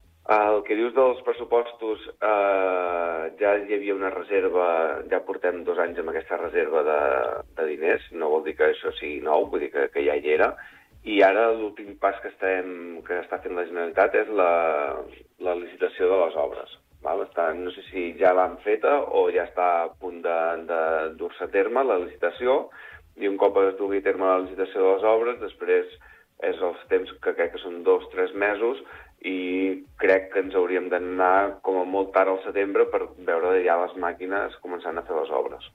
EntrevistesProgramesSupermatí